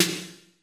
Snare - Roland TR 43